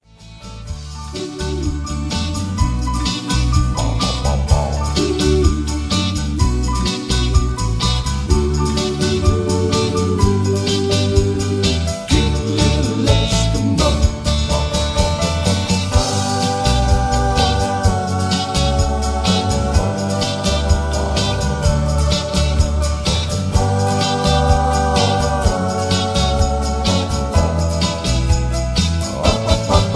Key-D) Karaoke MP3 Backing Tracks
Just Plain & Simply "GREAT MUSIC" (No Lyrics).